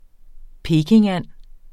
Udtale [ ˈpeːkeŋˌanˀ ]